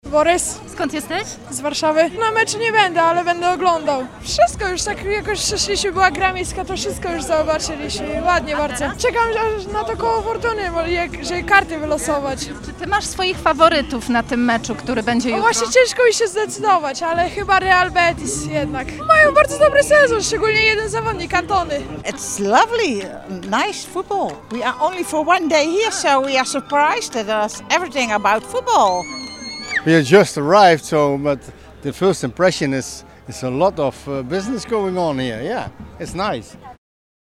Strefa Kibica w Rynku gromadzi tłumy z Polski i za granicy. Sprawdziliśmy ich nastroje.